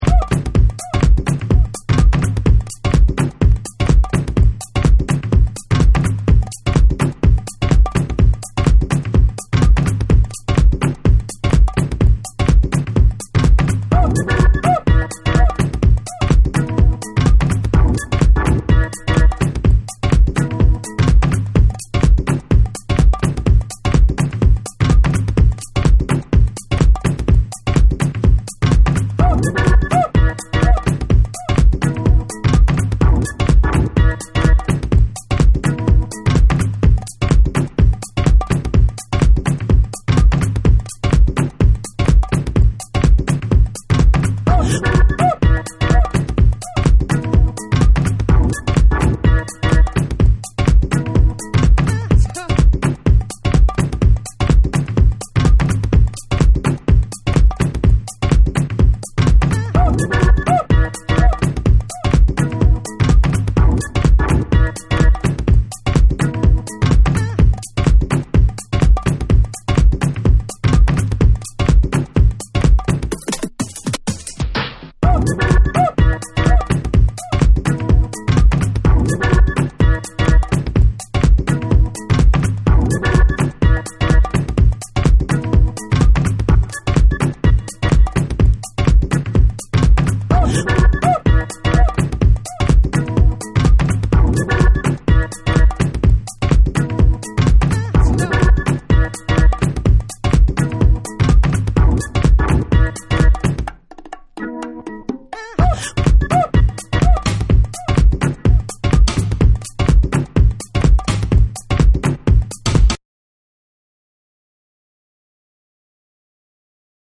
土着的なリズムにファンキーなヴォイスサンプルのカットアップ、ジャジーなエレピが絡み展開するパーカッシヴ・ミニマル
コチラもジャジーでパーカッシヴなグルーヴィー・ミニマル
TECHNO & HOUSE